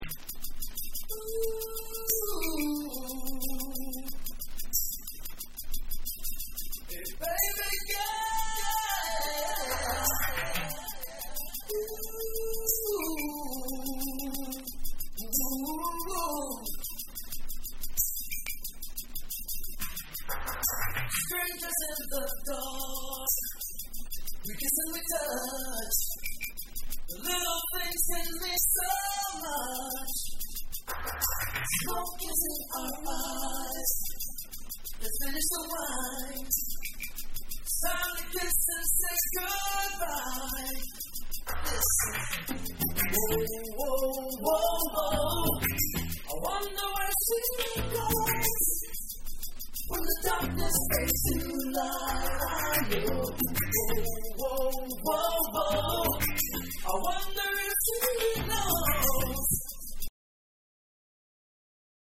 Acapella Version